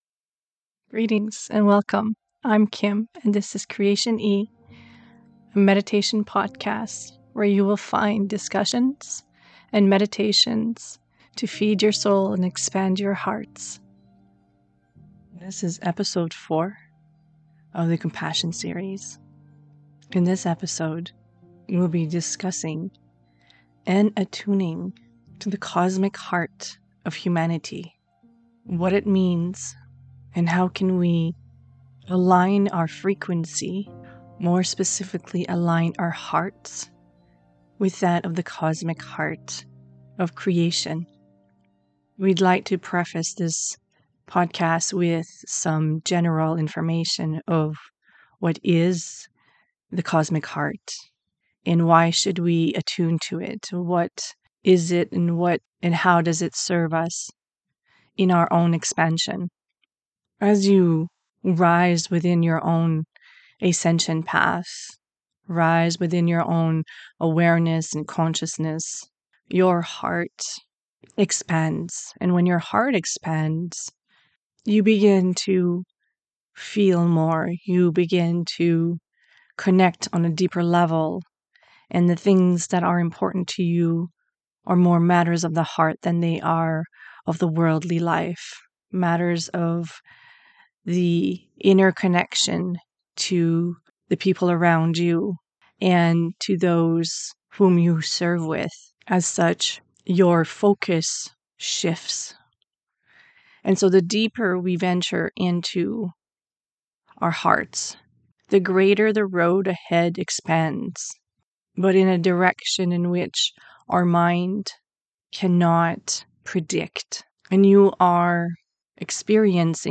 This episode is a discussion and channeled message about the attunement of your own higher heart with that of the Cosmic Heart of Creation. We explore the importance of getting to know our higher heart and how it works so that we may use it to connect to humanity's heart and assist in the transmutation of the lower densities of pain and fear in the collective field. Key topics in this discussion involve the higher heart of our soul, ascension path and our soul's evolution, how to use the higher heart and attune our system to its vibrational frequency.